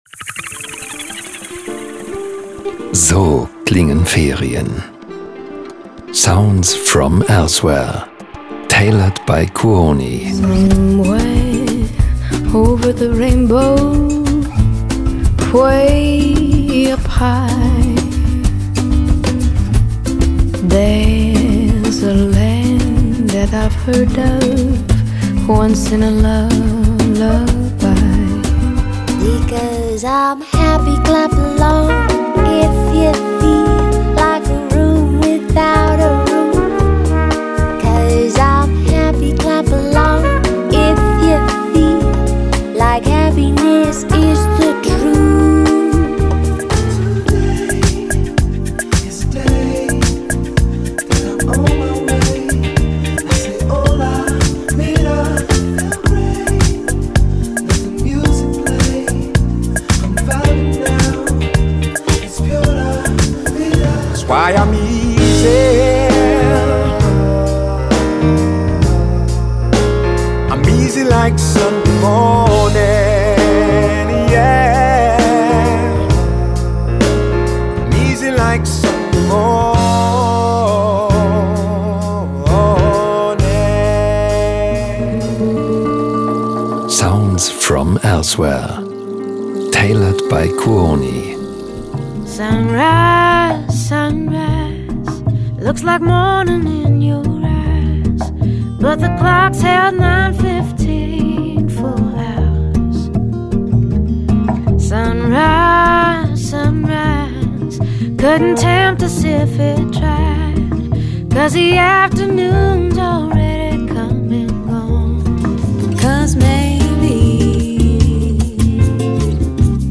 „Sounds from elsewehre tailored by Kuoni“ – Sounddemo
Das Programm kombiniert sorgfältig kuratierte Musik mit gezielten Werbebotschaften in 3 Sprachen.
Die Hörer:innen erwartet ein Mix sommerlichen Klängen und globalen Grooves – interpretiert von Künstler:innen wie Norah Jones, Till Brönner, Teddy Swims, Diana Krall oder Carla Bruni.
KUONI-Compilation-mit-Jingles-2025.mp3